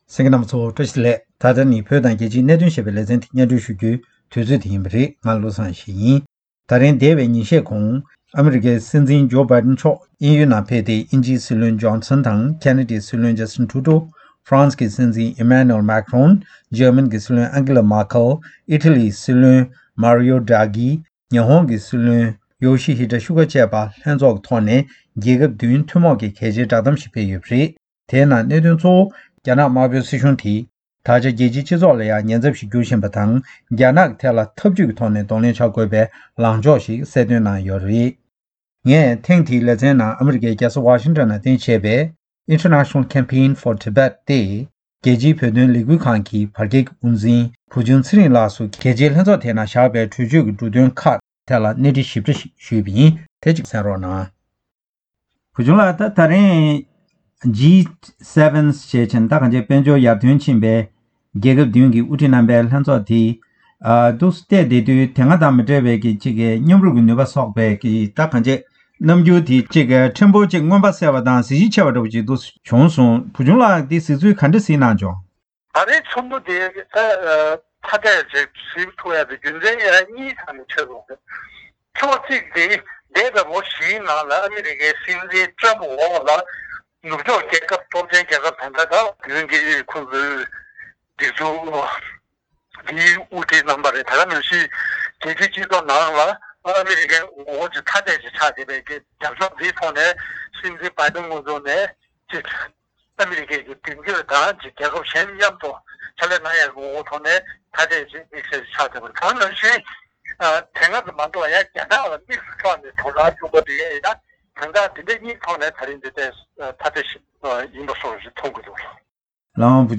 གནས་དྲིས་ཞུས་པ་དེ་སྙན་སྒྲོན་གནང་བར་གསན་རོགས།།